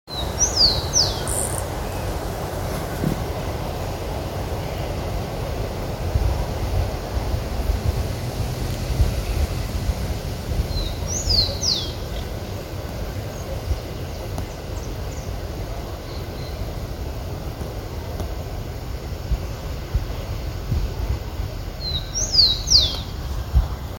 Chingolo (Zonotrichia capensis)
Nombre en inglés: Rufous-collared Sparrow
Localidad o área protegida: Concordia
Condición: Silvestre
Certeza: Fotografiada, Vocalización Grabada
Chingolo.mp3